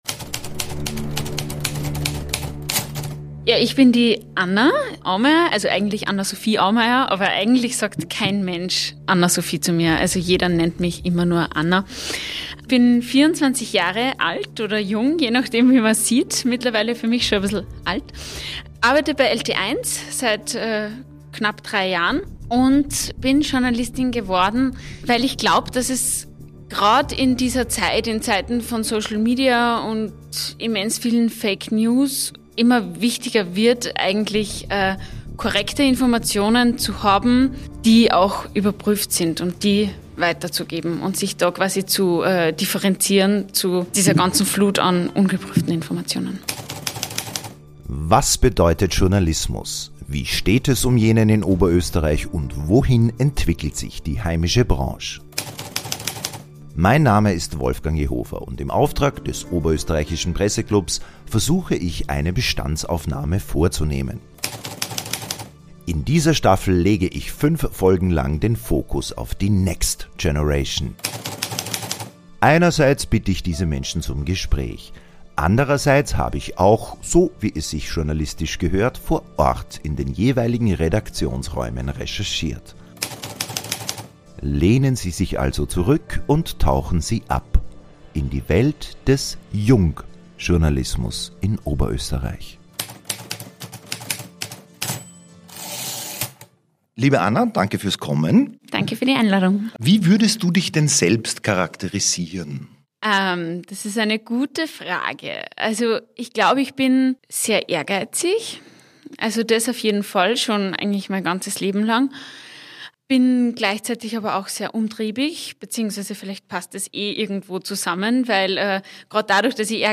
Aktuell schließt sie den Master in Journalismus und Medienmanagement ab. Im Gespräch ging es unter anderem um … … Warum sie Journalistin wurde?